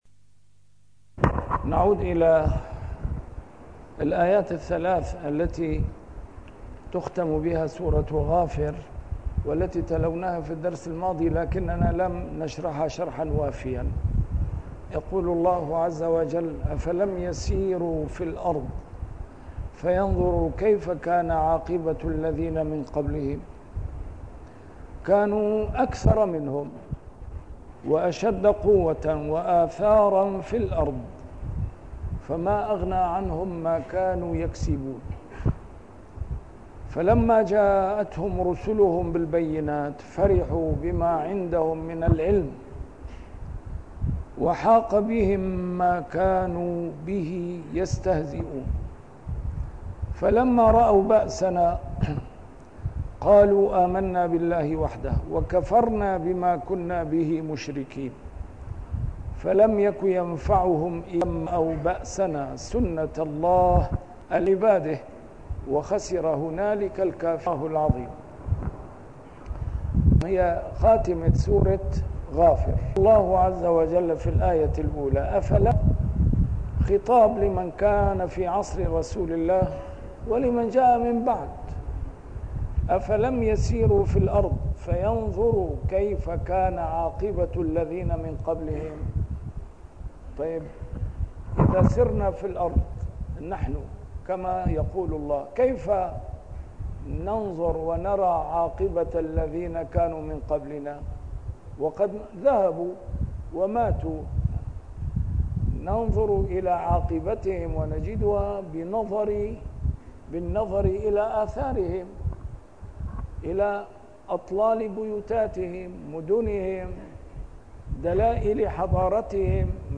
نسيم الشام › A MARTYR SCHOLAR: IMAM MUHAMMAD SAEED RAMADAN AL-BOUTI - الدروس العلمية - تفسير القرآن الكريم - تسجيل قديم - الدرس 531: غافر 82-85